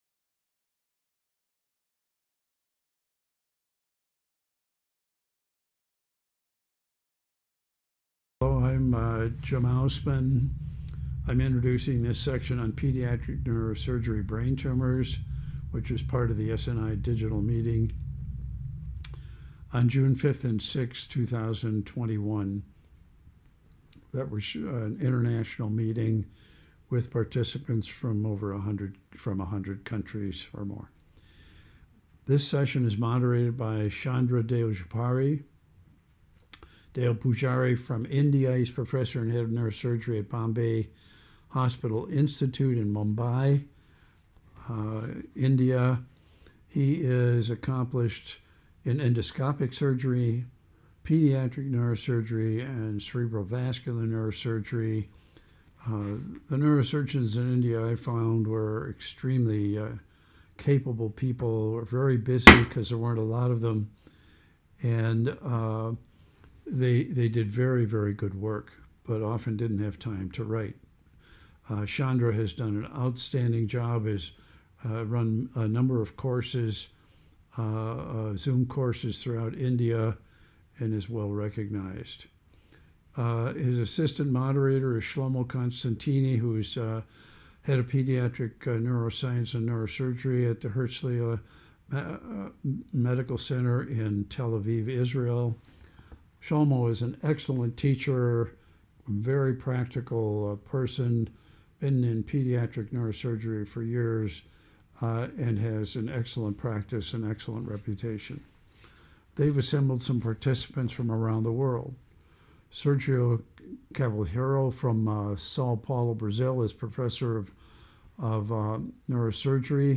Pediatric Neurosurgery-Brain Tumors; Global experience; Round table Discussion; Case based; Craniopharyngeoma; Endoscopic Surgery; Medulloblastoma; Radiation Therapy; Genetic Data